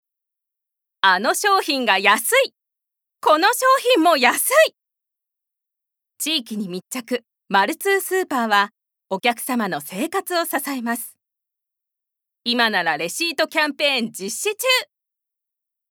ボイスサンプル
ナレーション２